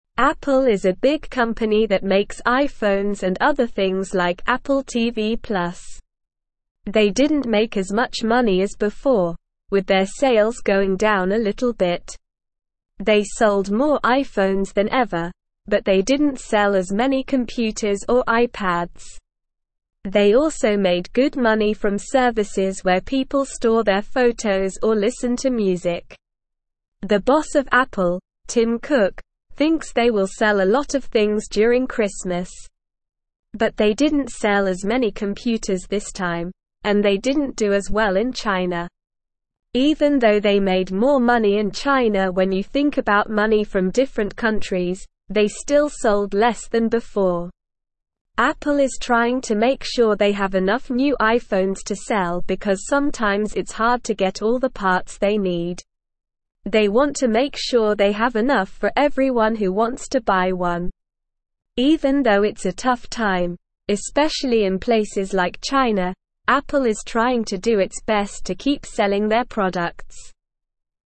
Slow
English-Newsroom-Lower-Intermediate-SLOW-Reading-Apple-Sells-Many-Things-But-Not-As-Many.mp3